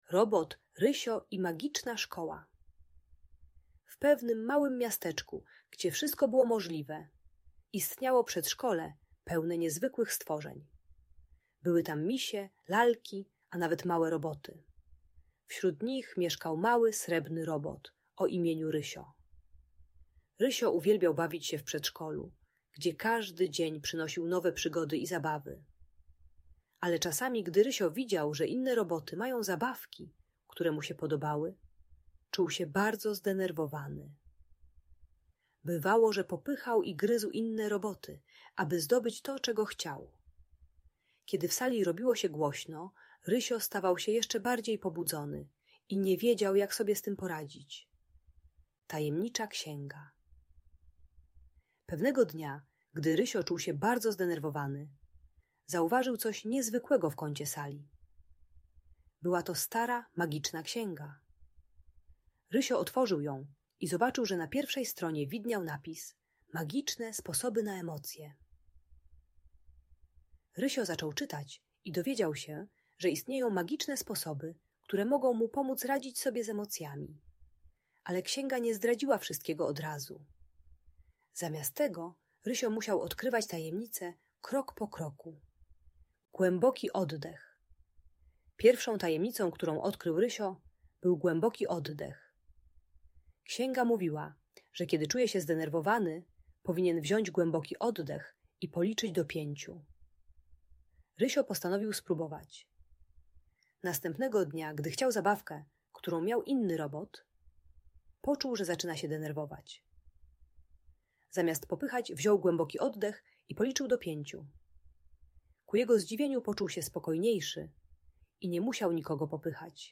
Robot Rysio i Magiczna Szkoła - story o emocjach - Audiobajka